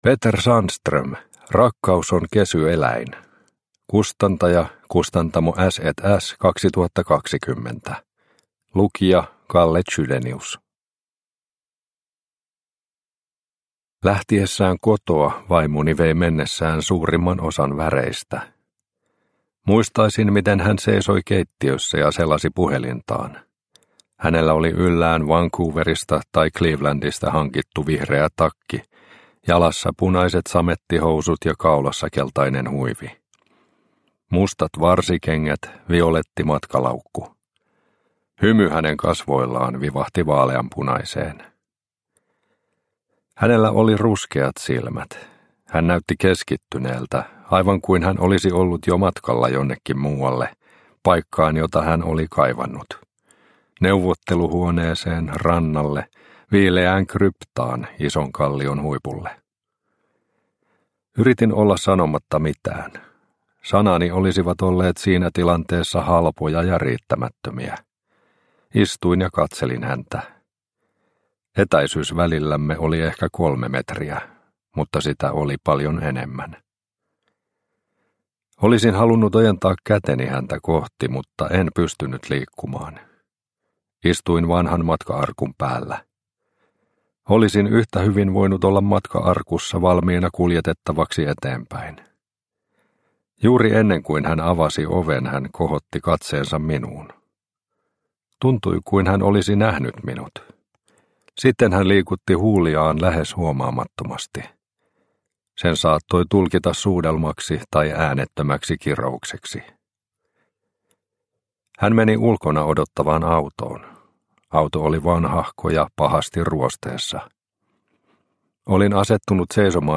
Rakkaus on kesy eläin – Ljudbok – Laddas ner